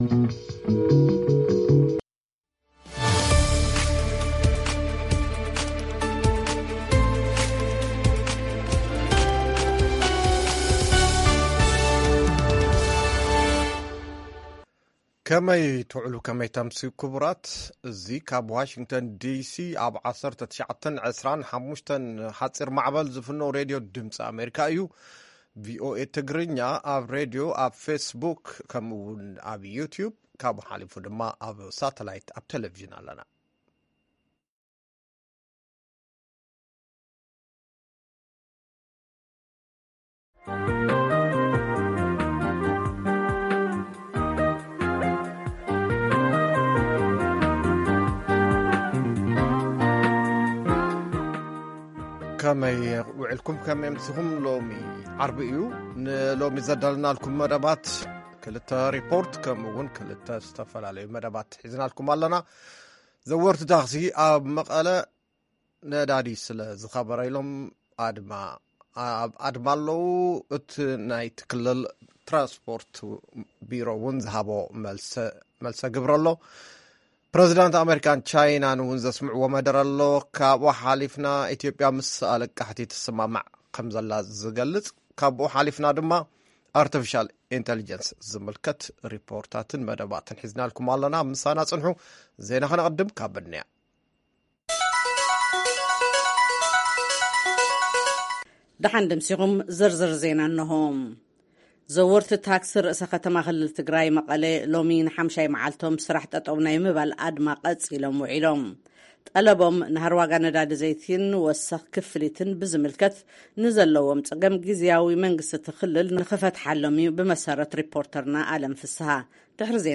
ፈነወ ድምጺ ኣመሪካ ቋንቋ ትግርኛ 17 ሕዳር 2023 ዞባዊ ኣህጉራዊን ዓለምለኸ ዜና ጸብጻብ ስራሕ ደው ናይ ምባል ኣድማ ዘወርቲ ታክሲ መቐለ ቃለ መሕትት ኣብ ጉዳይ ሰብ ስርሖ ብልሒ የጠቓልል።